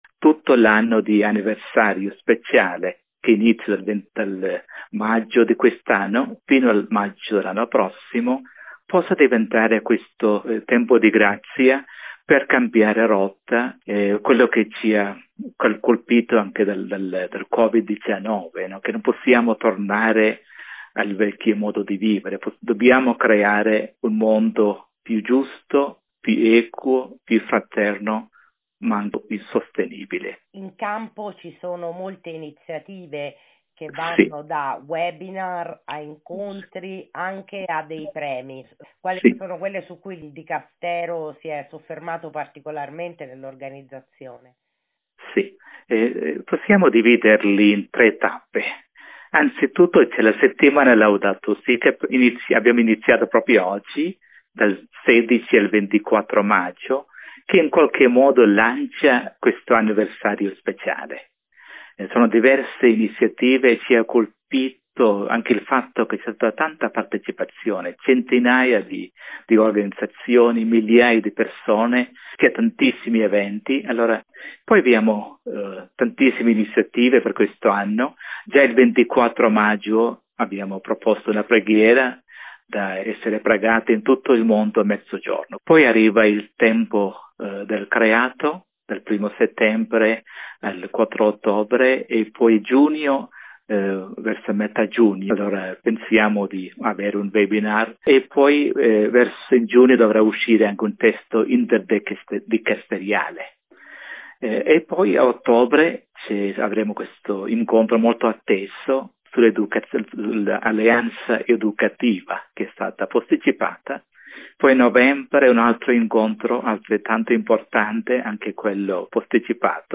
Intervista-per-Laudato-si.mp3